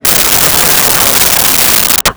Crowd Laughing 09
Crowd Laughing 09.wav